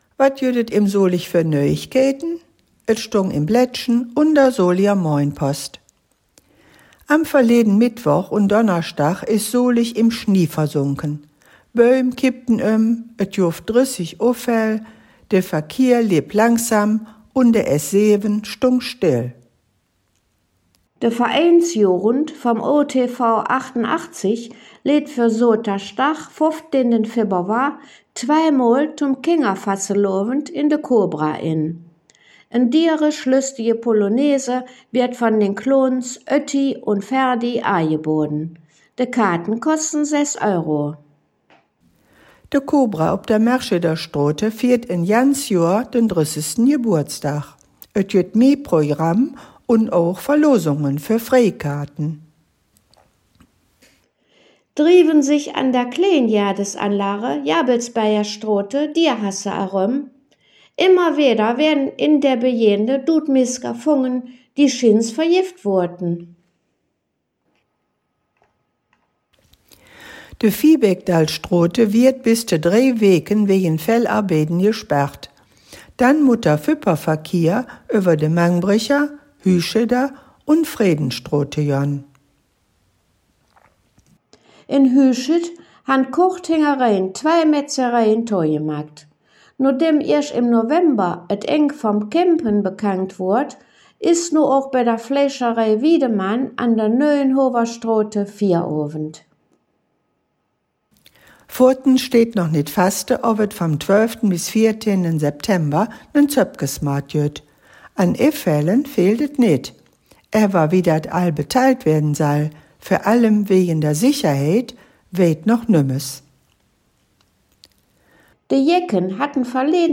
Nöüegkeïten op Soliger Platt - Nachrichten in Solinger Platt